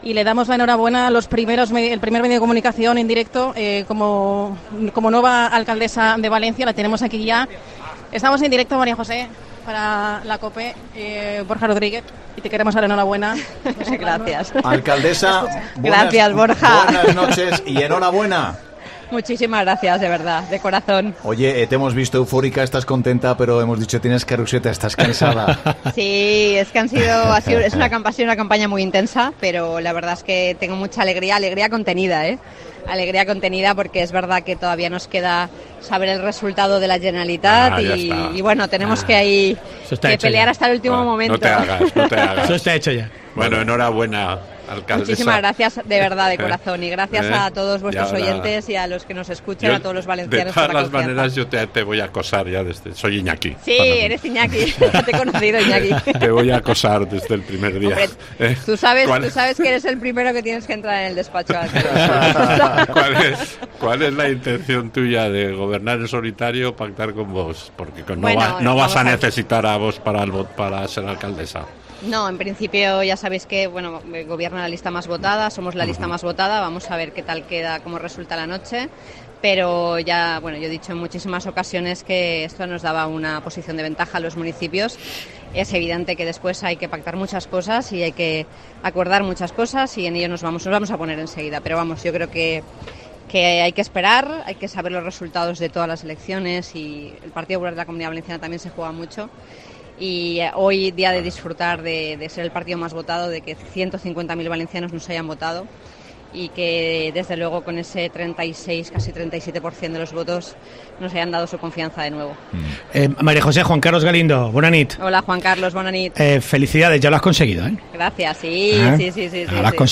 EXCLUSIVA | Primeras declaraciones de María José Catalá